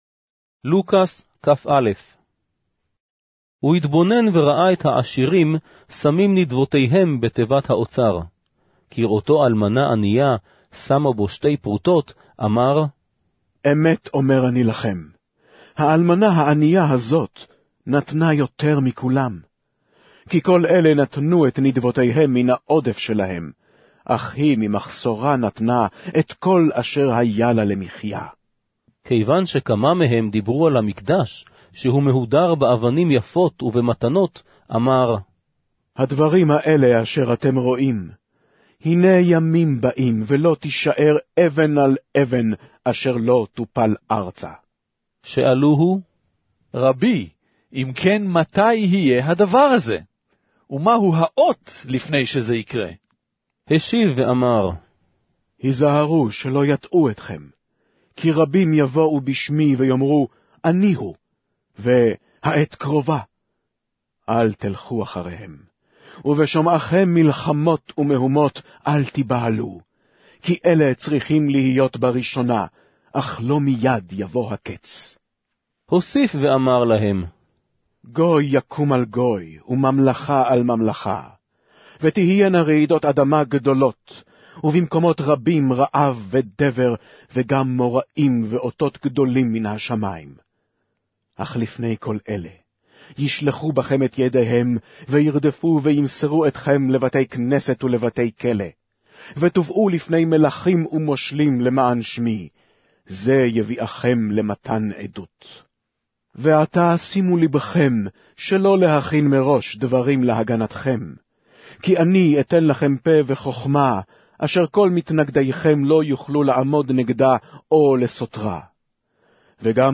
Hebrew Audio Bible - Luke 22 in Tov bible version